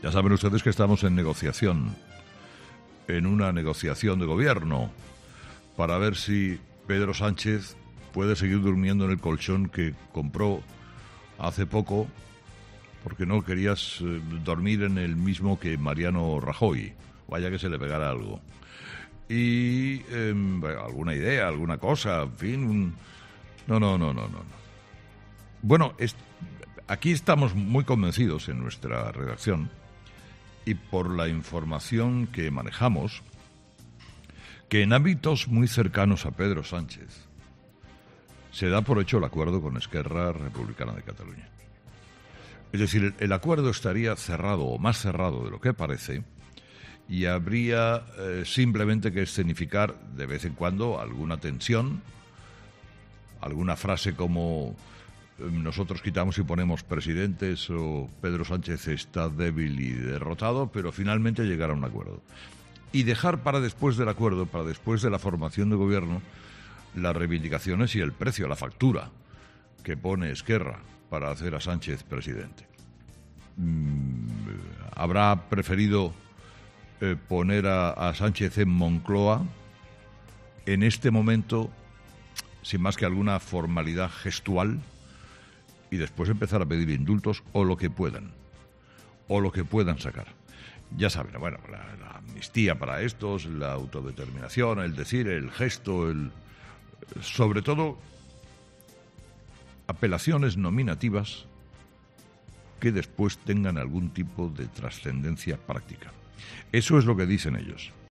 Carlos Herrera ha comenzado su monólogo de las 06.00 explicando a los oyentes lo que para él es a todas luces una impostura de Sánchez y ERC, en la que el acuerdo entre la formación independentista y el PSOE estaría ya cerrada.